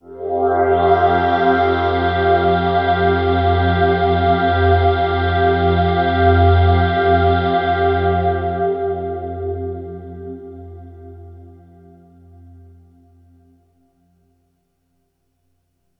Here’s a test with the same 3 sounds on both hardware and software, all recorded into Digitakt II.